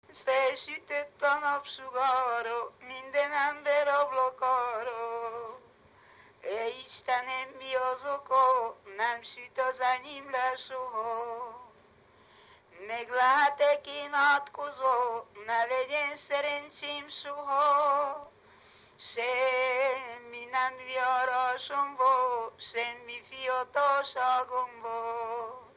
Erdély - Csík vm. - Rakottyástelep (Gyimesbükk)
Műfaj: Keserves
Stílus: 3. Pszalmodizáló stílusú dallamok
Szótagszám: 8.8.8.8
Kadencia: 5 (b3) 1 1